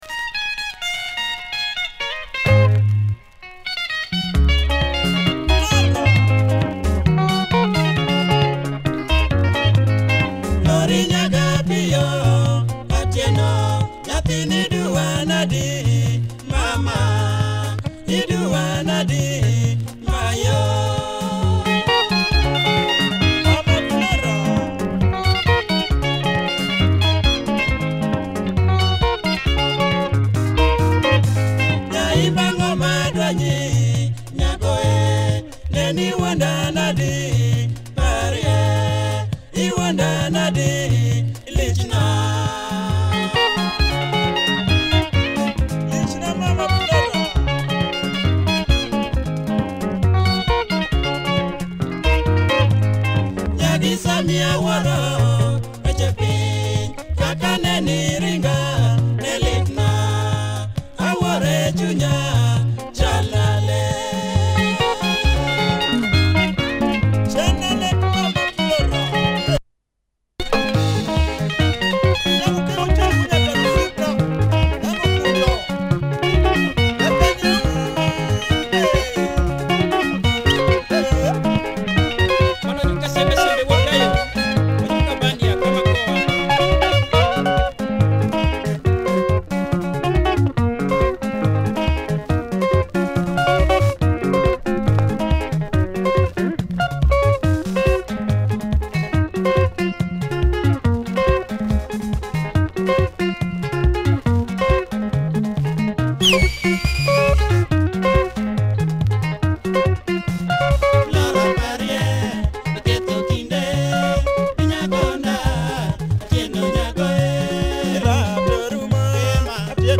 Nice LUO benga
good groove and guitar